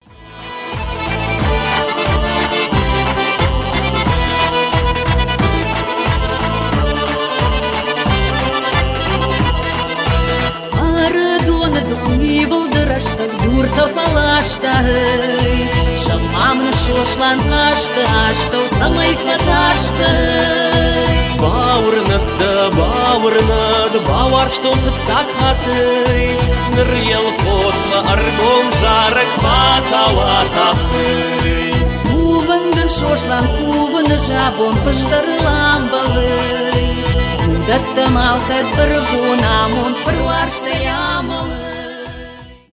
кавказские , лезгинка , русские , дуэт , поп